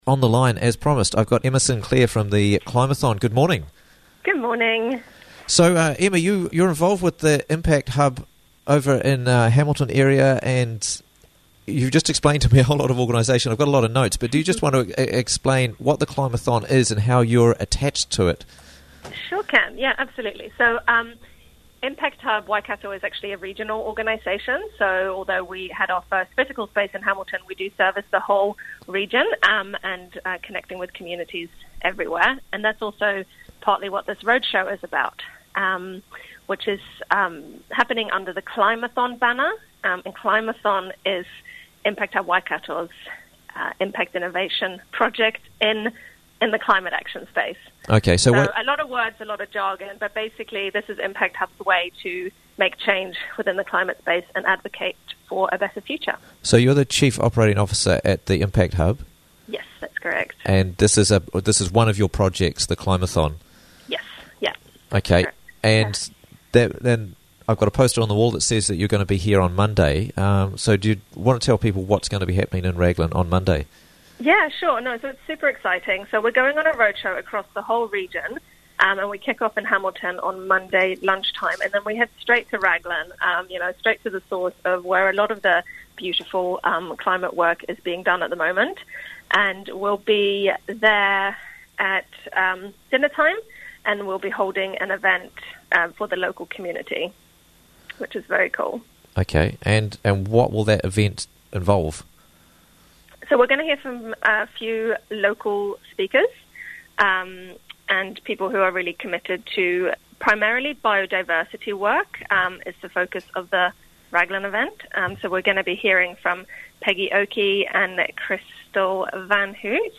Climathon Visiting Raglan on Monday - Interviews from the Raglan Morning Show